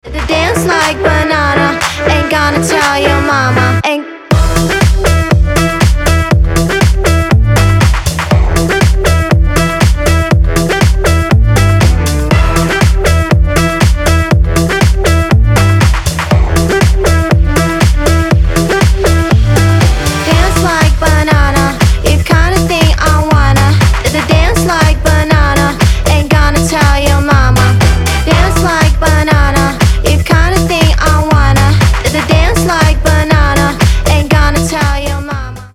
• Качество: 320, Stereo
забавные
веселые
женский голос
Dance Pop
Веселая песенка про танцы в стиле банана :)